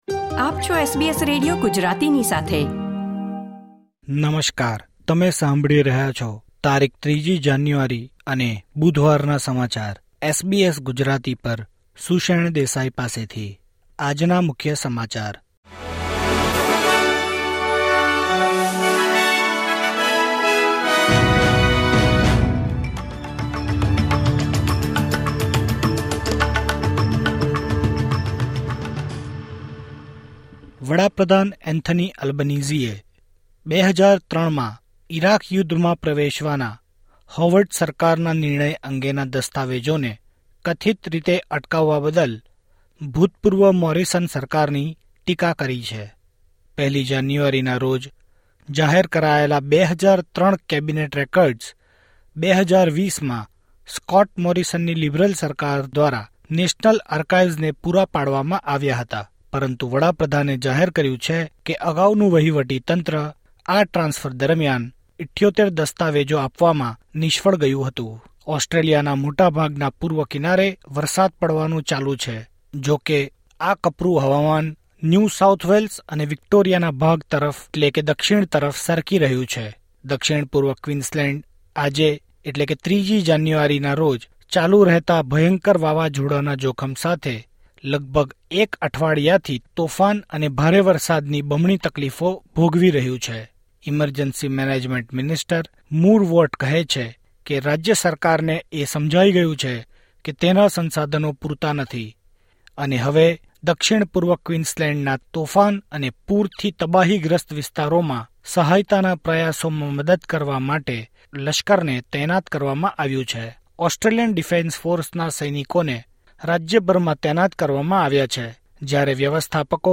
SBS Gujarati News Bulletin 3 January 2024